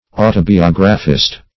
Search Result for " autobiographist" : The Collaborative International Dictionary of English v.0.48: Autobiographist \Au`to*bi*og"ra*phist\, n. One who writes his own life; an autobiographer.